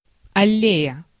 Ääntäminen
IPA : /ˈæli/